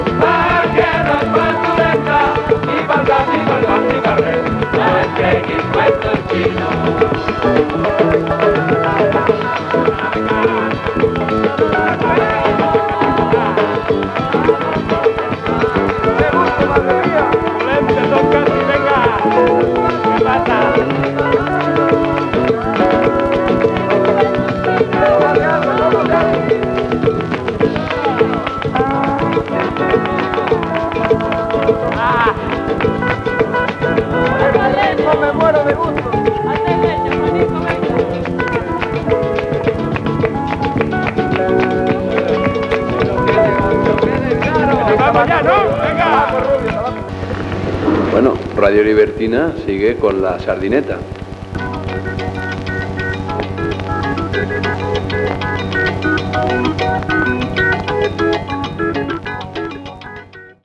Tema musical i identificació de l'emissora.